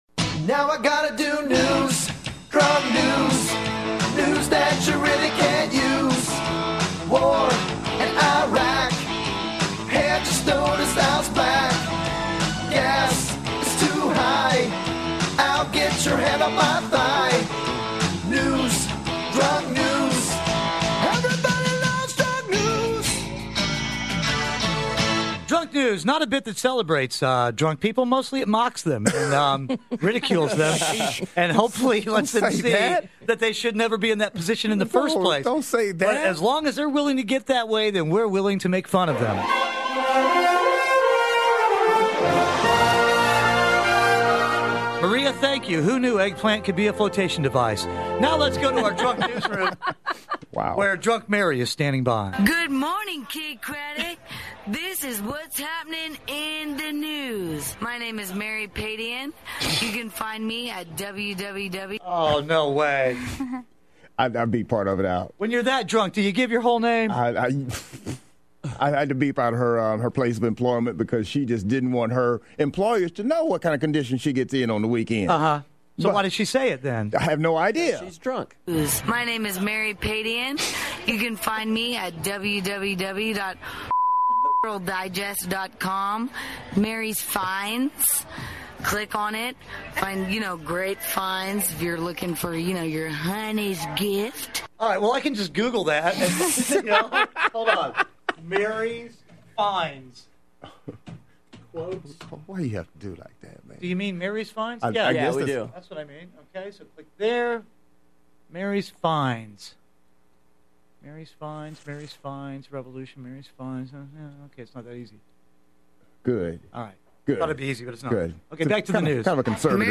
The cast of Kidd Kraddick in the Morning guess how drunk random bystanders are based on the way they deliver the news!